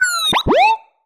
sci-fi_driod_robot_emote_04.wav